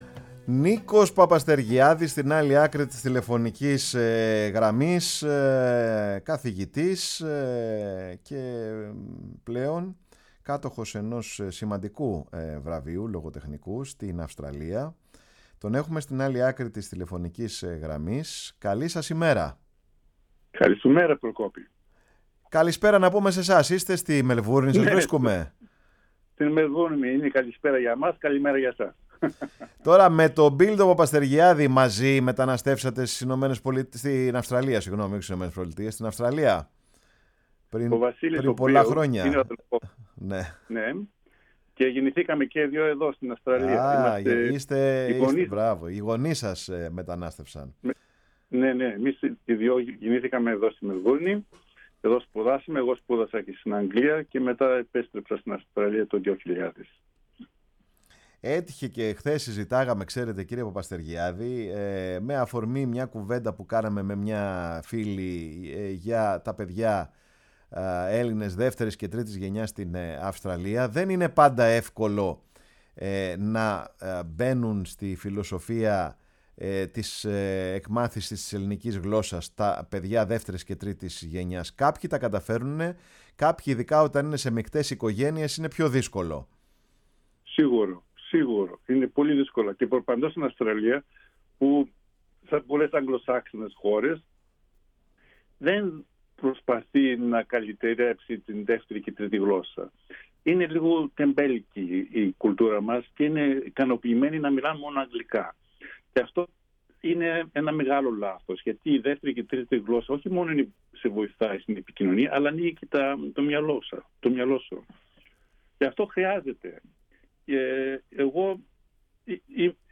ήταν καλεσμένος στην εκπομπή “Πάρε τον Χρόνο σου”, στη Φωνή της Ελλάδας